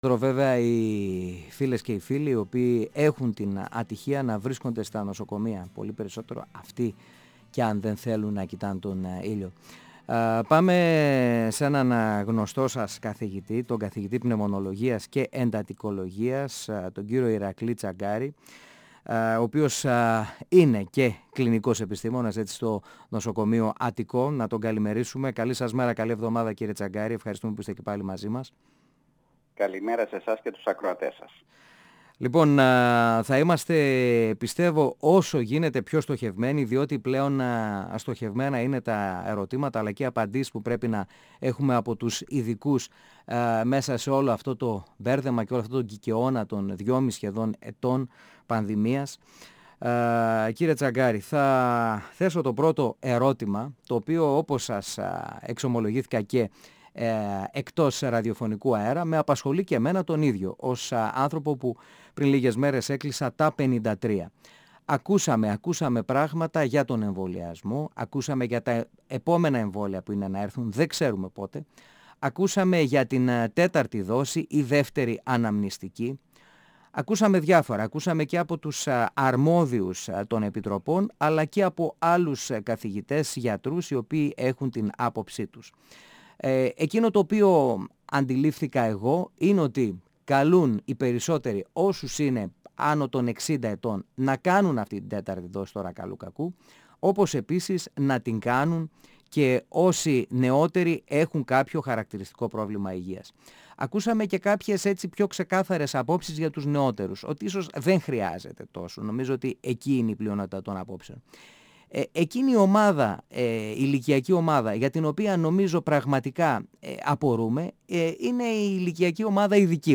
Σχετικά με την αναγκαιότητα 4ης δόσης εμβολιασμού, παρότι ο ίδιος επιλέγει τον εμβολιασμό, τονίζει πως λείπουν επαρκή επιστημονικά δεδομένα και αυτό δικαιολογεί την αμηχανία, που χαρακτηρίζει μεγάλο μέρος της κοινωνίας. 102FM Συνεντεύξεις ΕΡΤ3